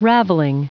Prononciation audio / Fichier audio de RAVELLING en anglais
Prononciation du mot : ravelling